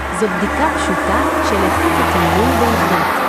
noise.wav